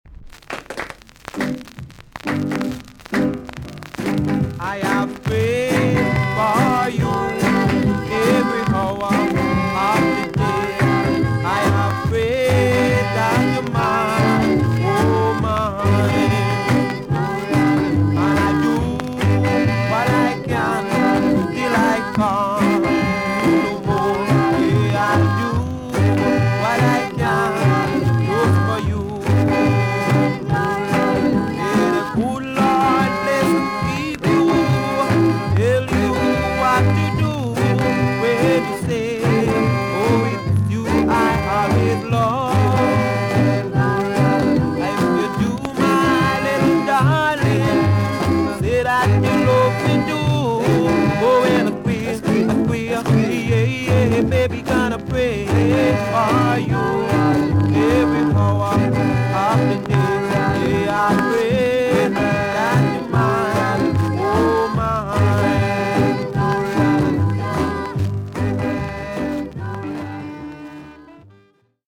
TOP >SKA & ROCKSTEADY
VG ok 全体的に軽いチリノイズが入ります。